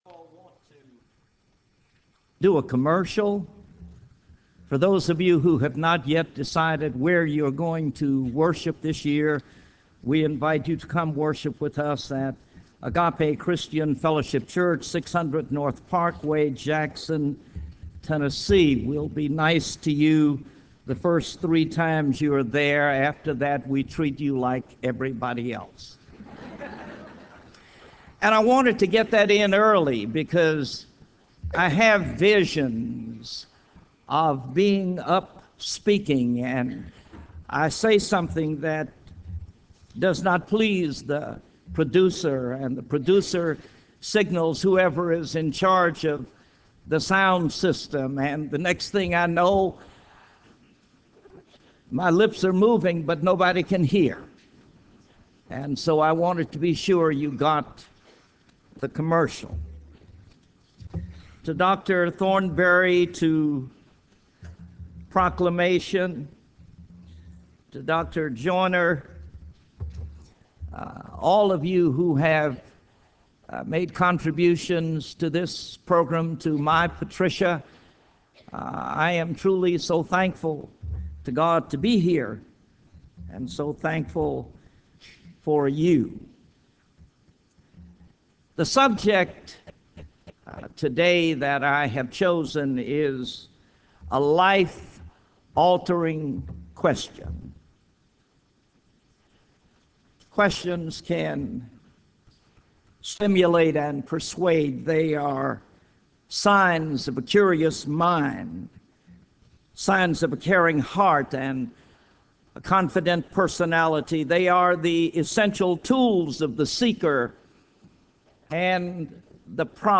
Address: "A Life-Altering Question" from Esther 4:14 Recording Date